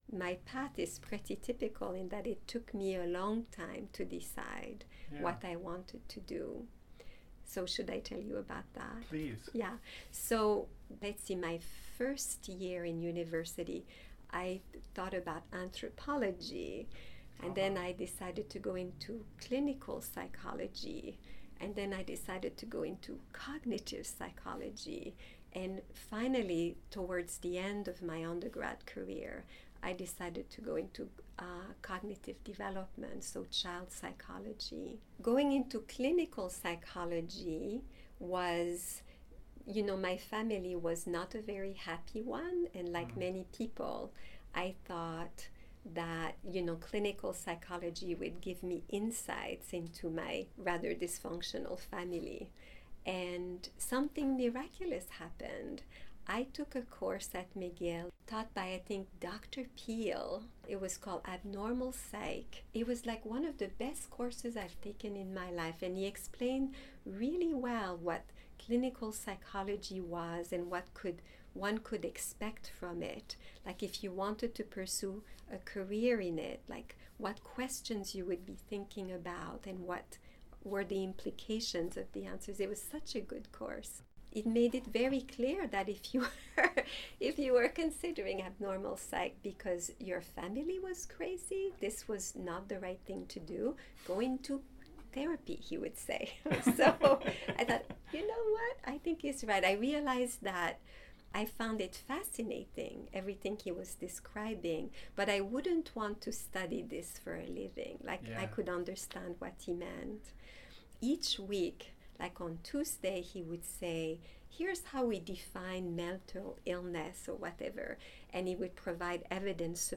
She describes finding her major and the eventual path to her undergrad thesis here: